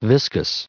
added pronounciation and merriam webster audio
809_viscous.ogg